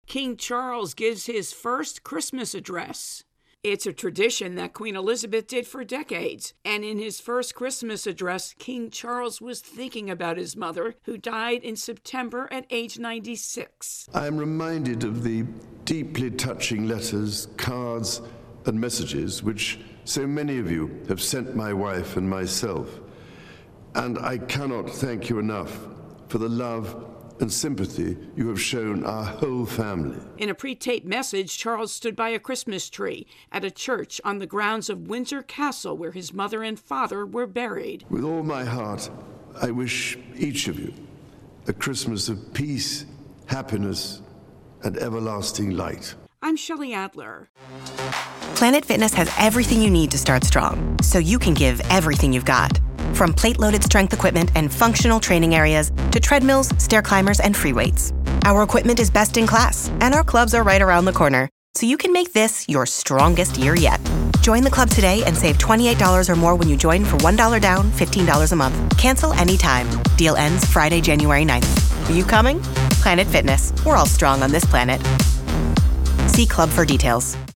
reports on King Charles' address.